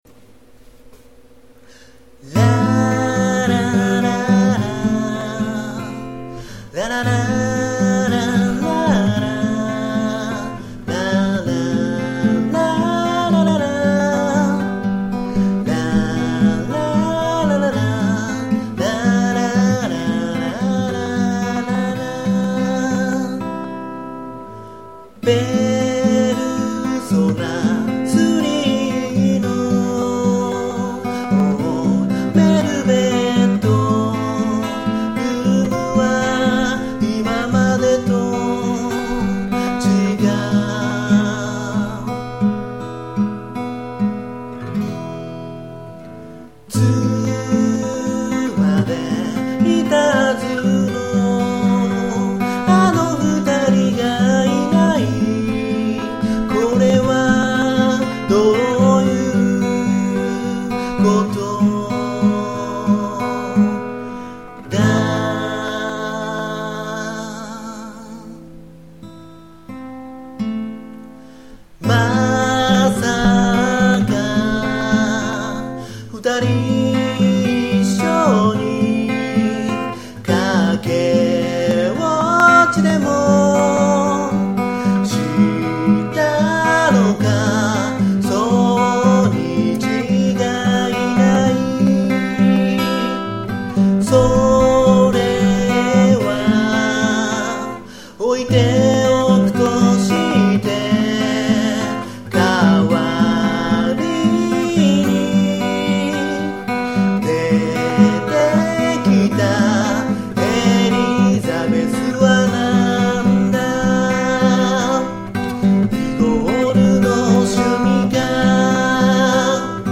録音環境が整ってないので音割れしまくるのは仕様！！
音割れ酷いし出来上がりも微妙なのでいずれ録り直したい作品。